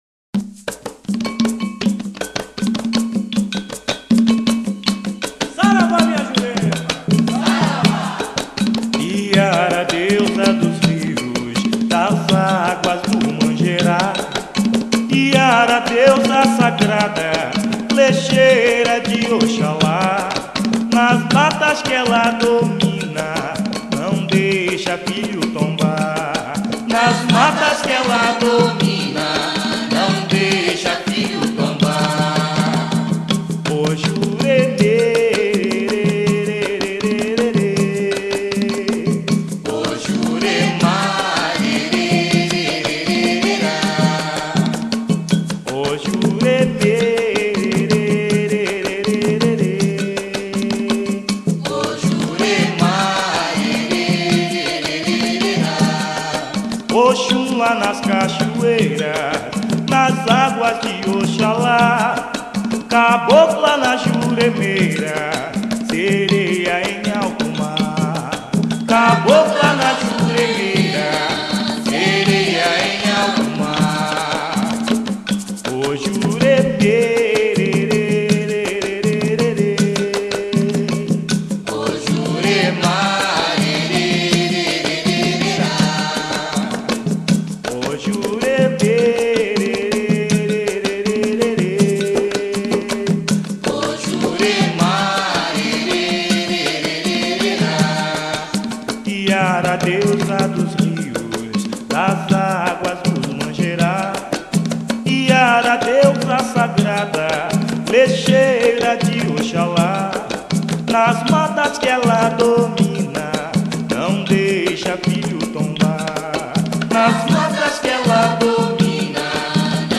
PONTOS CANTADOS